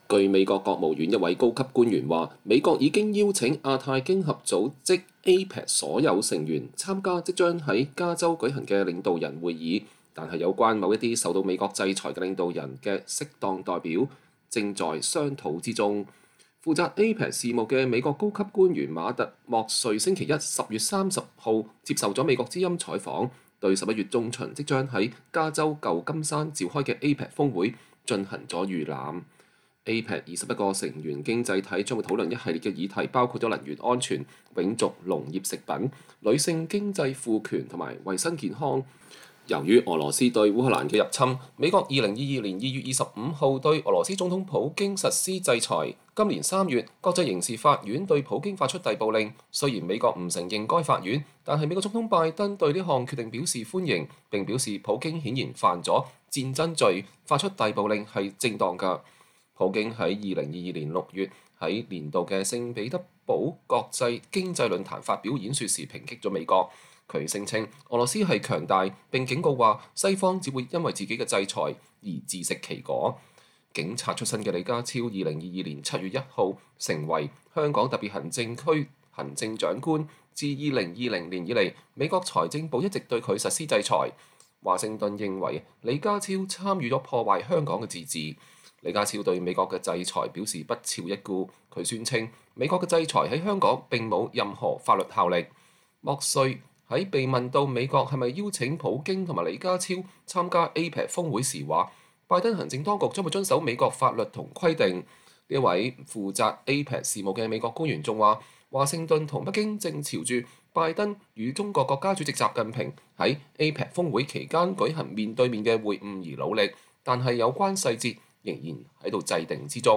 負責APEC事務的美國高級官員馬特·默裡(Matt Murray)週一(10月30日)接受美國之音採訪，對11月中旬即將在加州三藩市召開的APEC峰會進行了預覽。